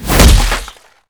kick2.wav